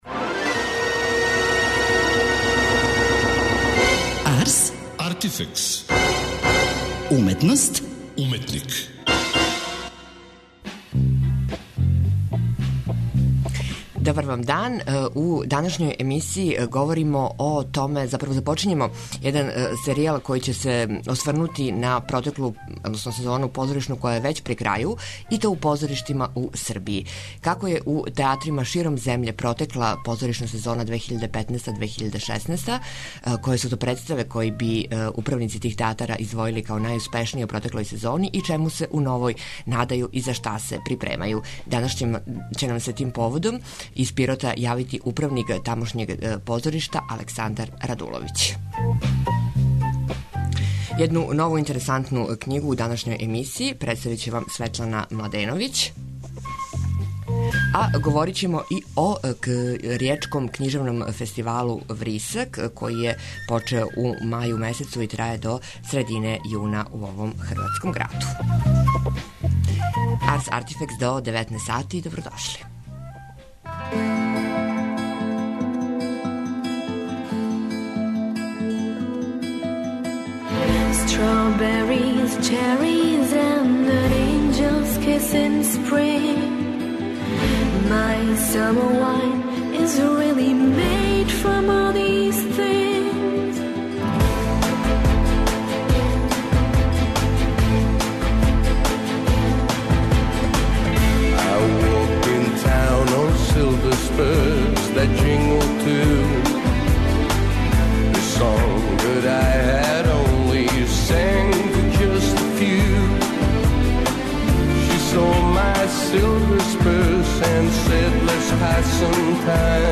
Раде Шербеџија нам се јавља из Ријеке, са 'Вриска' - ријечког књижевног фестивала.